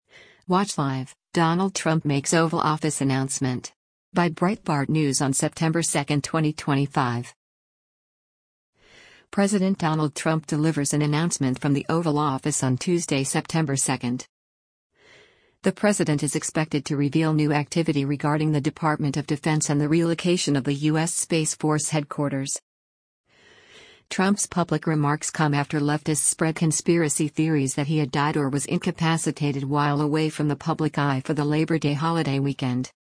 President Donald Trump delivers an announcement from the Oval Office on Tuesday, September 2.